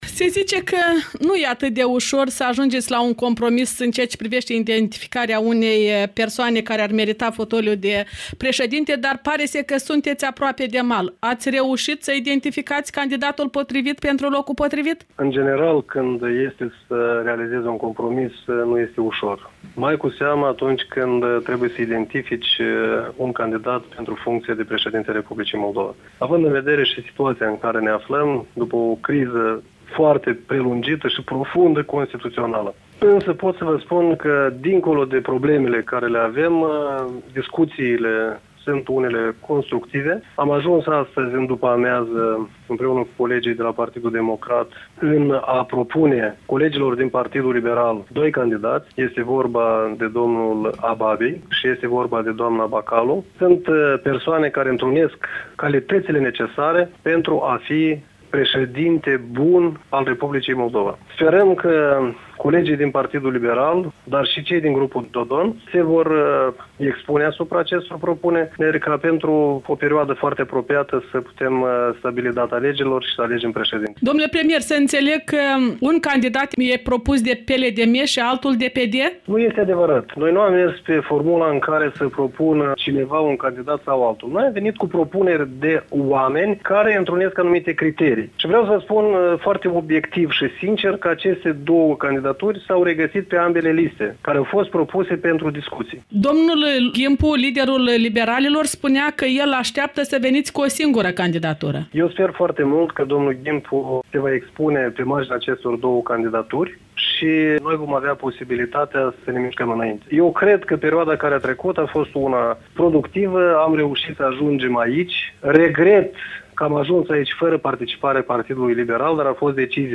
Vlad Filat, preşedintele PLDM, în dialog